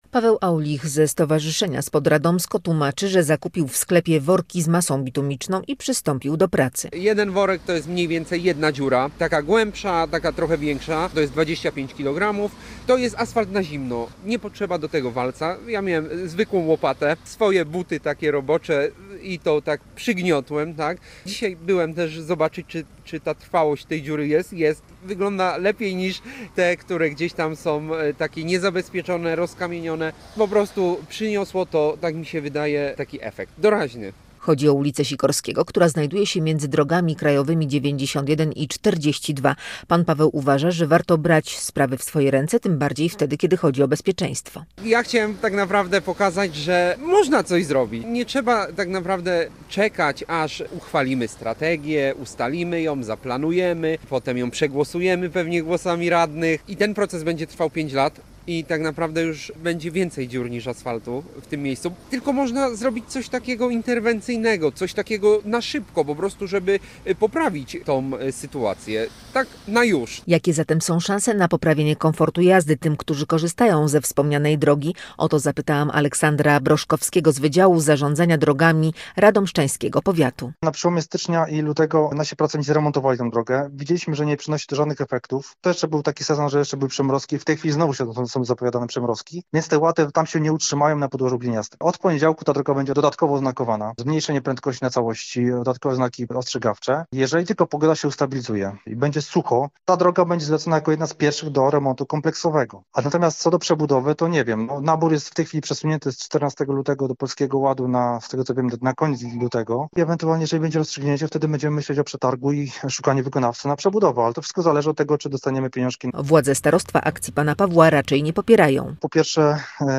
Posłuchaj relacji i dowiedz się więcej: Nazwa Plik Autor – brak tytułu – audio (m4a) audio (oga) Jak się dowiedzieliśmy, na tej drodze planowana jest przebudowa, jest to jednak uzależnione od pozyskania środków zewnętrznych.